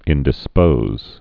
(ĭndĭ-spōz)